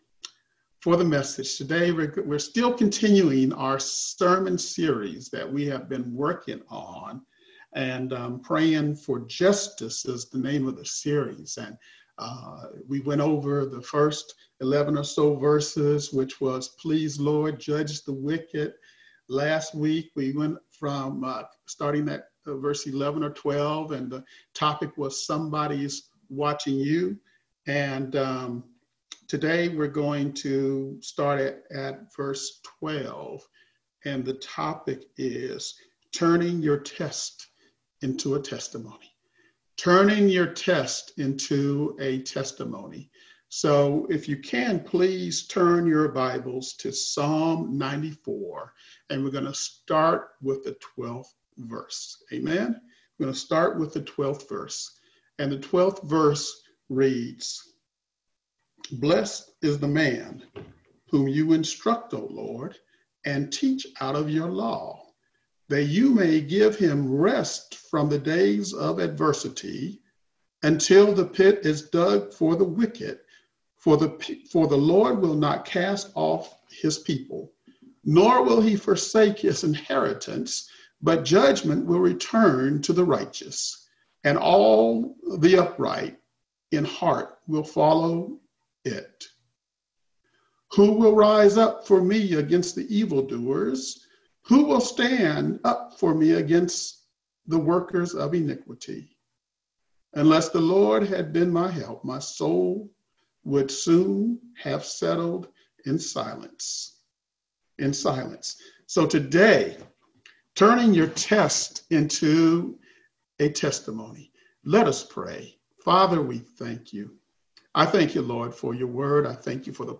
Virtual Service for Sunday, June 21, 2020 Message Topic: Praying for Justice - Turning Your Test into a Testimony Scripture: Psalm 94:12-15